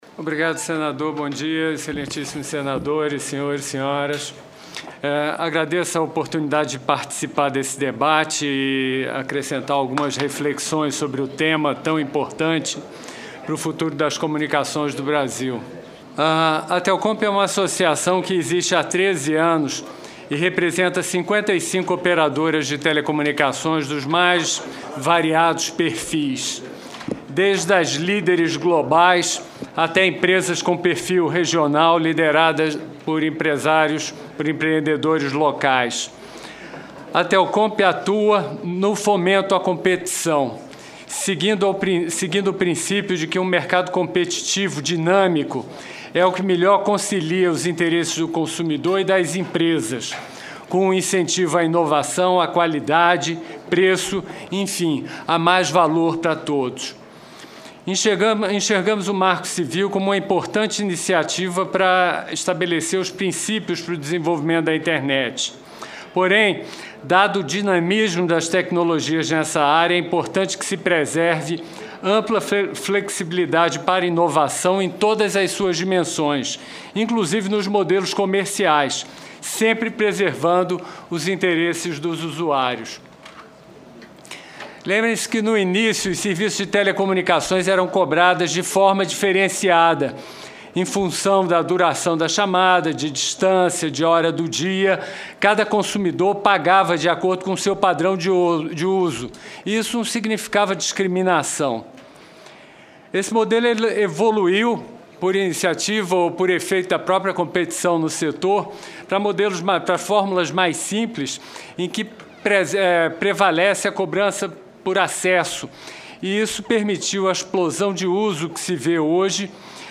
Confira a íntegra dos principais debates da Comissão de Ciência, Tecnologia, Inovação, Comunicação e Informática do Senado